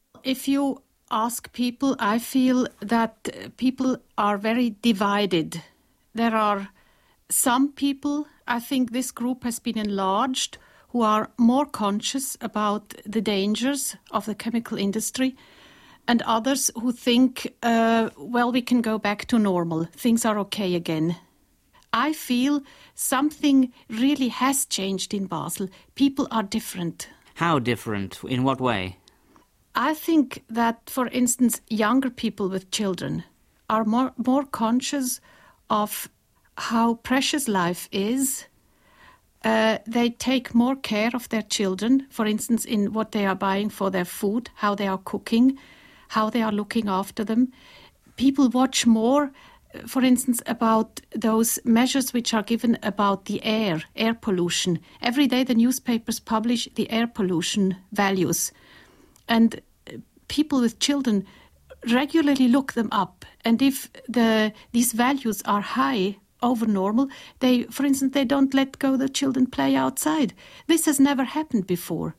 In this interview extract from Swiss Radio International in 1987